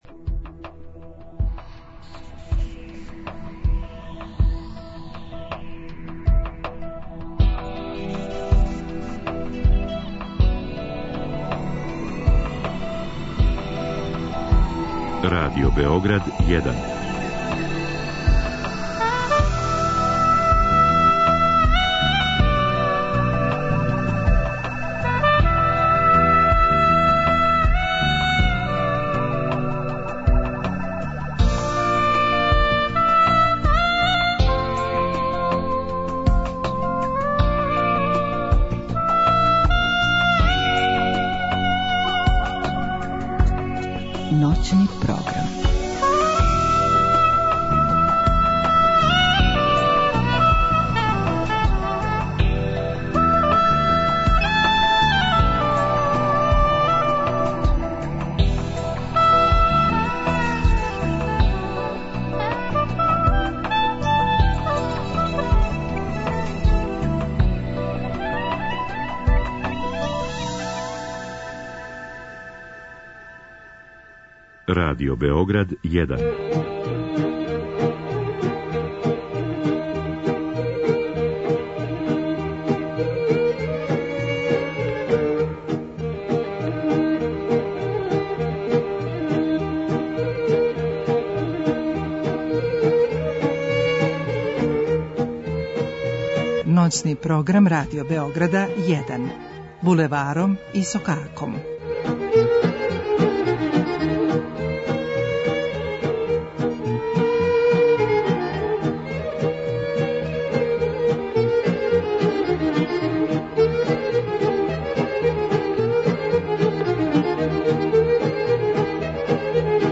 са слушаоцима ће бити водитељи и гости у студију